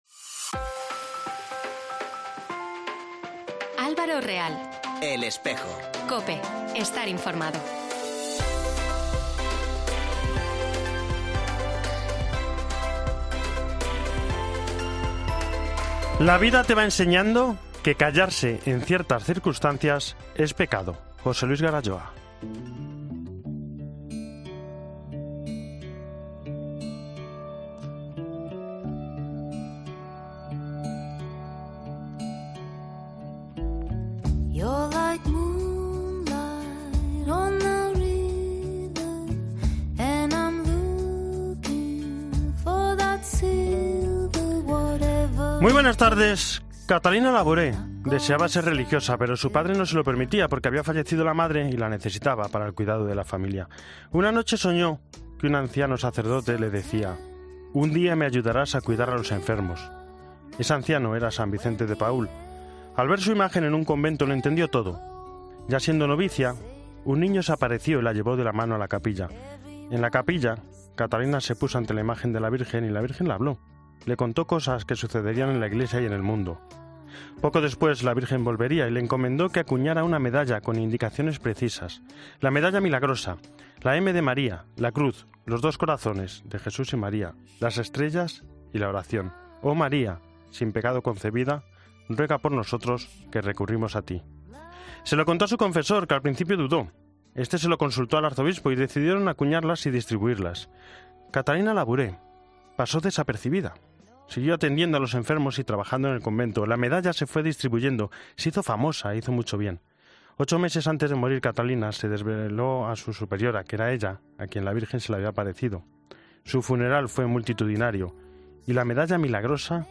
En Espejo 28 noviembre: Entrevista a Monseñor Elizalde sobre Trece casas